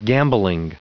Prononciation du mot gambolling en anglais (fichier audio)
Prononciation du mot : gambolling